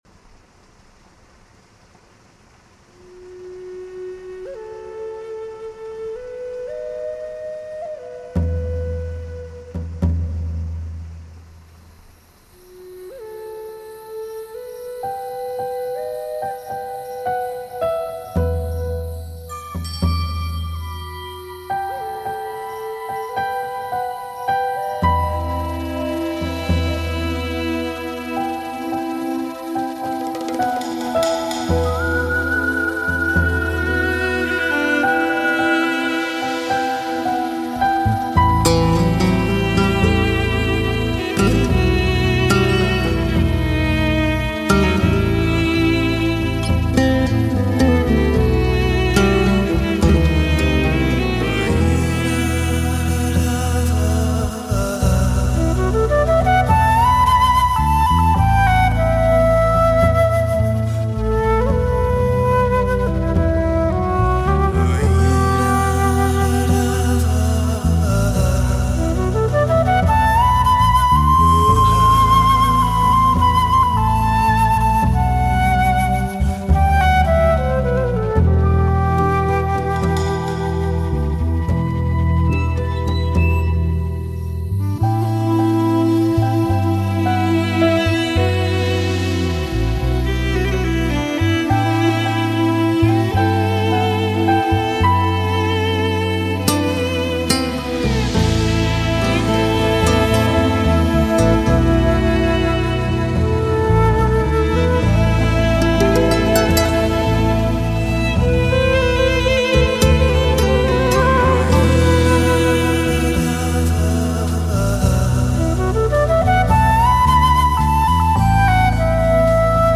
موسیقی بیکلام new age
نیو ایج